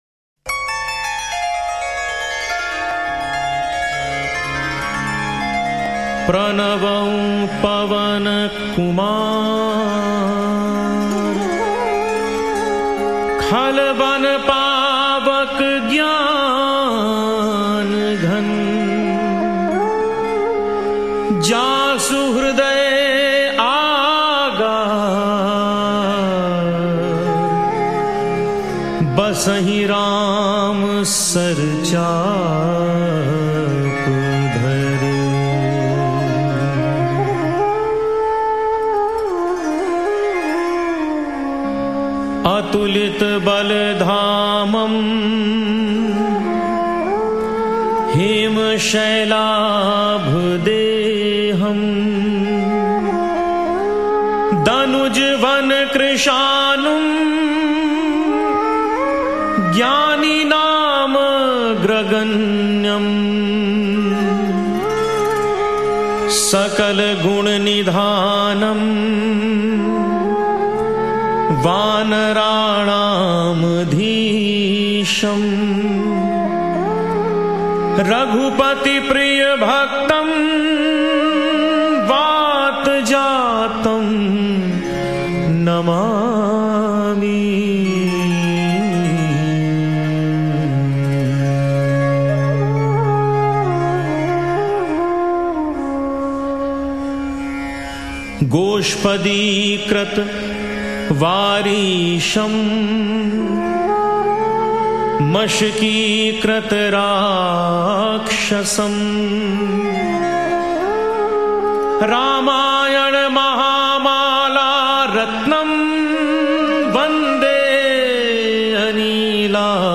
Shree Hanuman Bhajans